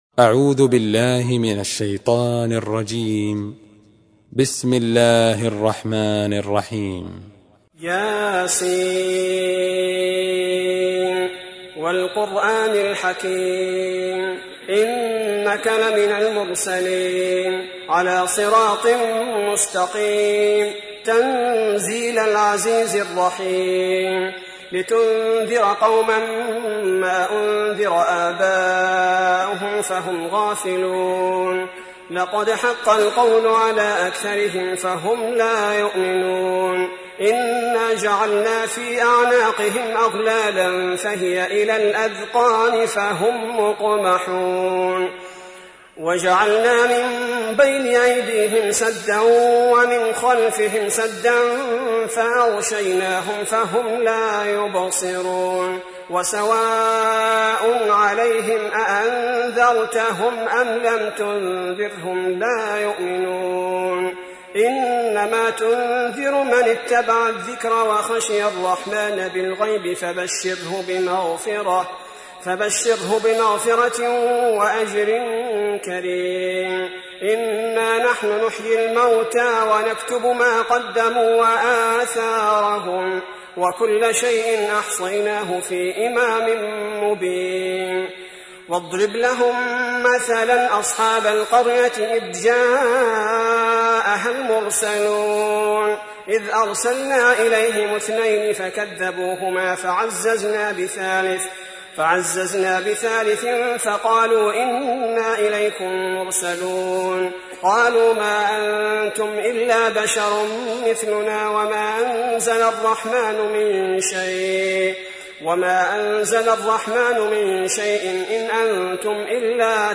تحميل : 36. سورة يس / القارئ عبد البارئ الثبيتي / القرآن الكريم / موقع يا حسين